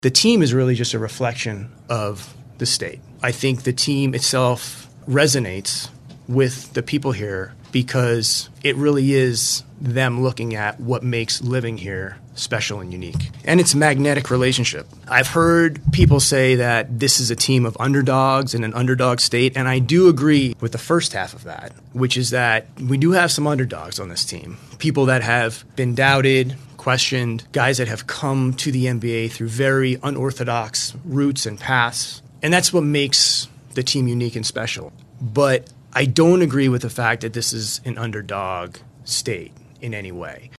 It was a lot of well-earned victory laps for Presti at the press conference,